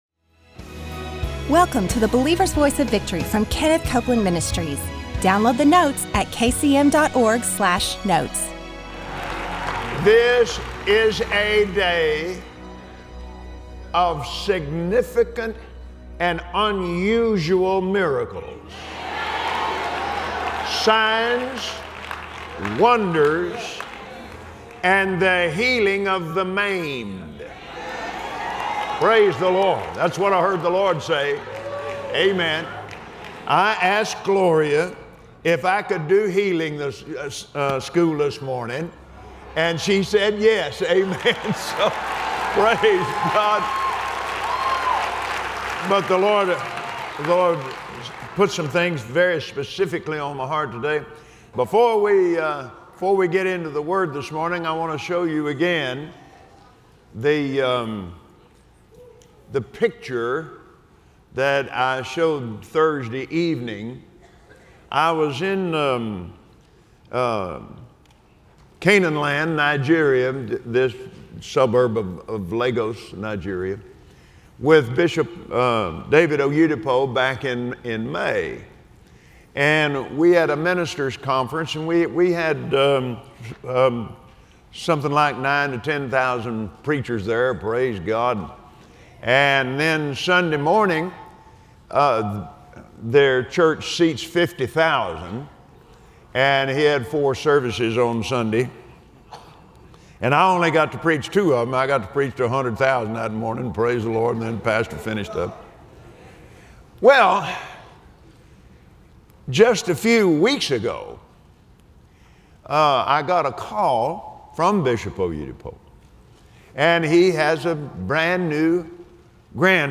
Learn how to activate your faith in God’s Word and get ready to receive your miracle as Kenneth Copeland teaches us about the healing power of God, on today’s Believer’s Voice of Victory.